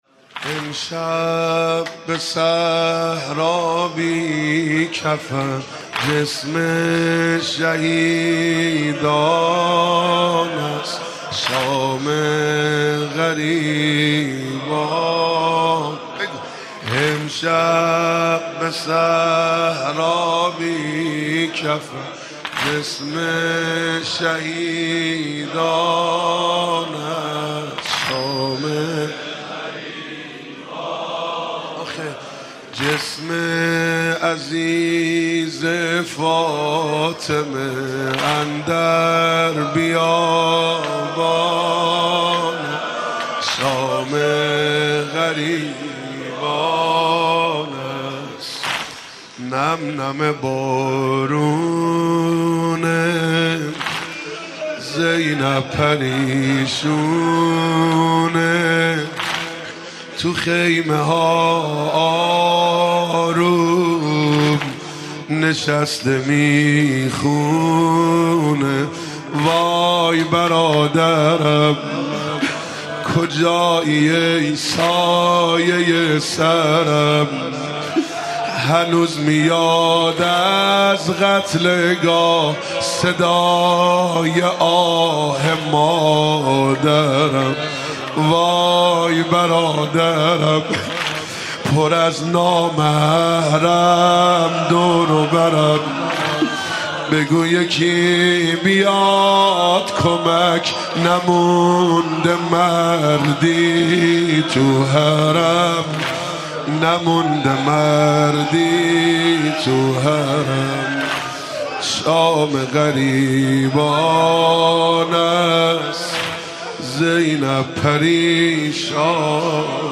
نوحه
مداحی شام غریبان